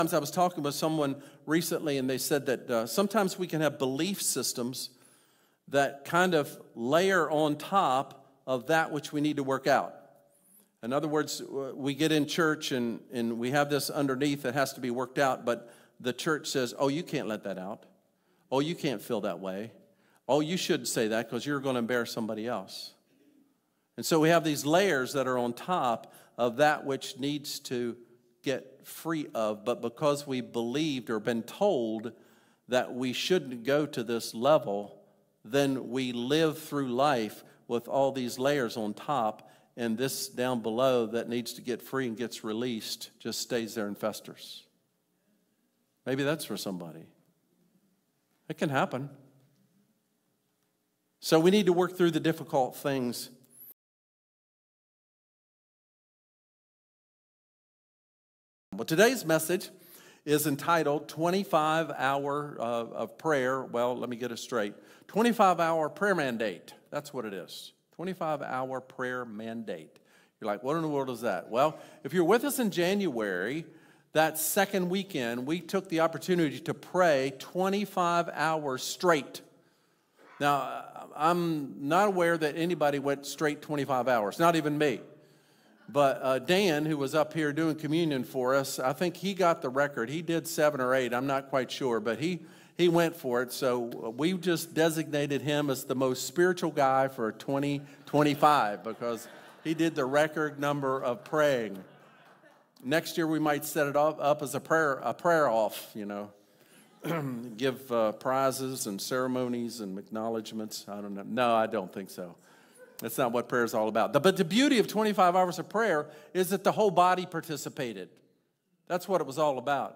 Sermon | Crossroads Community Church